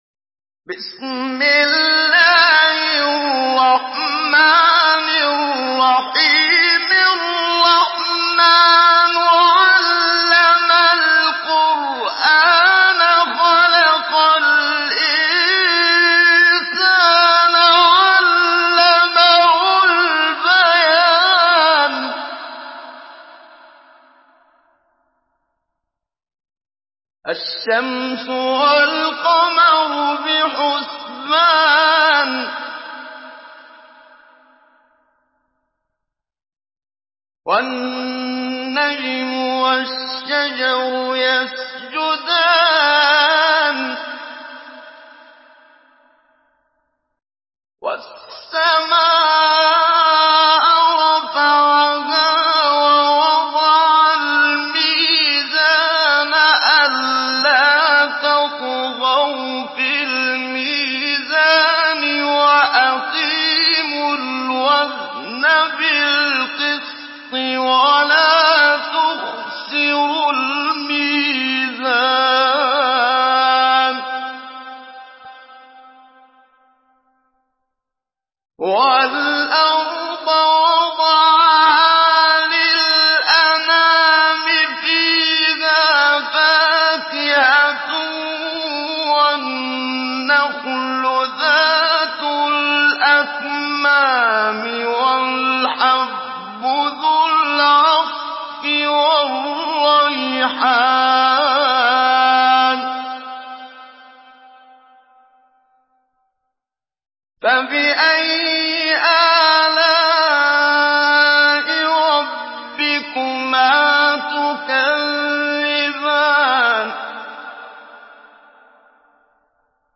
Surah Rahman MP3 in the Voice of Muhammad Siddiq Minshawi Mujawwad in Hafs Narration
Surah Rahman MP3 by Muhammad Siddiq Minshawi Mujawwad in Hafs An Asim narration. Listen and download the full recitation in MP3 format via direct and fast links in multiple qualities to your mobile phone.